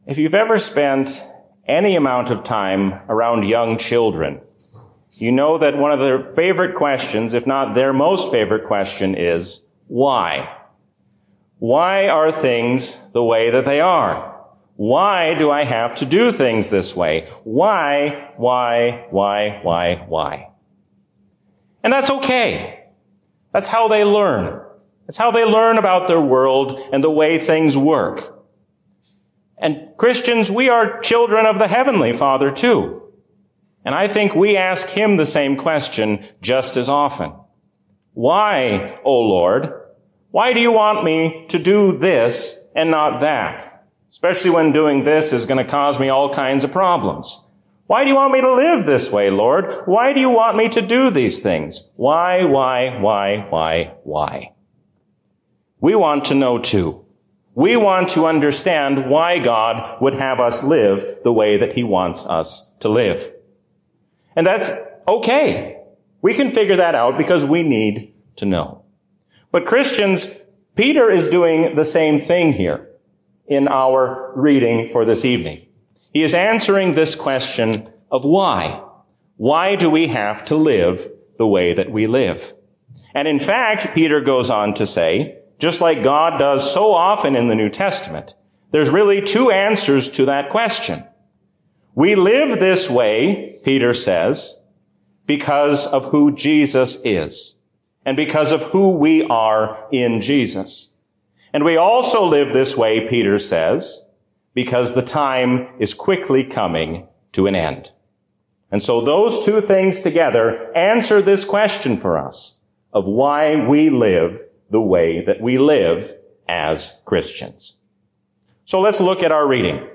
A sermon from the season "Trinity 2024." The New Jerusalem shows us what it will be like to be with God in glory forever.